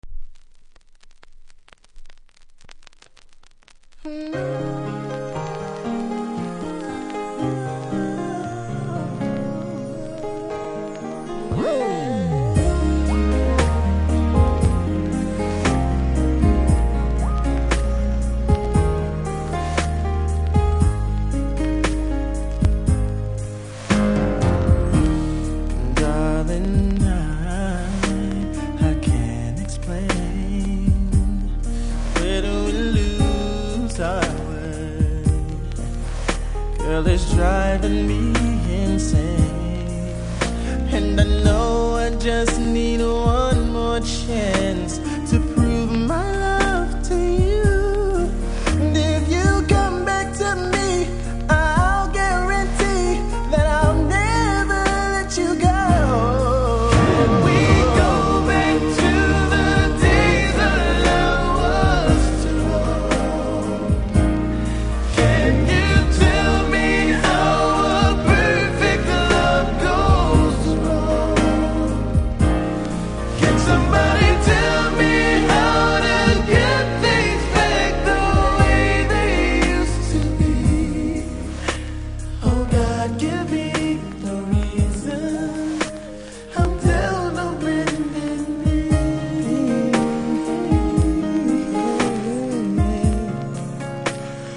ノイズ多々感じますので試聴で確認下さい。